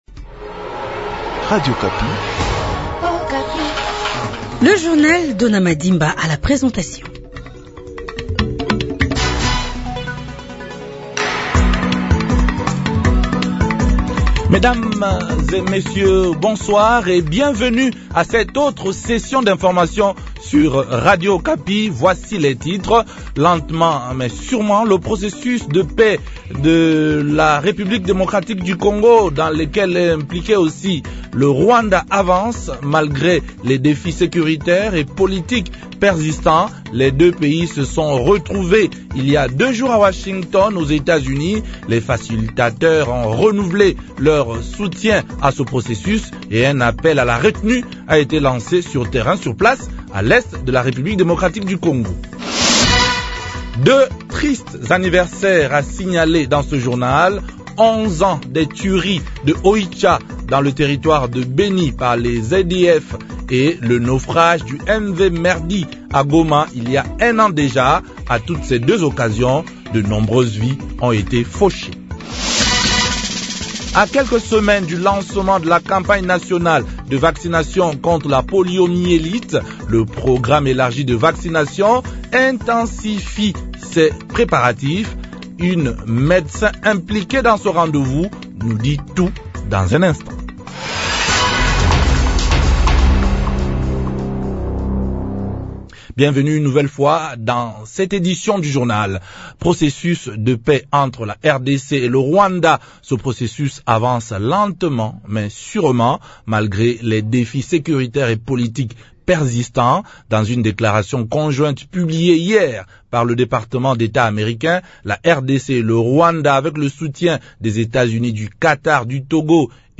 journal francais
A quelques semaines du lancement de la campagne nationale de vaccination contre la poliomyélite, le Programme élargi de vaccination (PEV) intensifie ses préparatifs. Une médecin impliquée dans ce rendez vous nous dit tout dans un instant.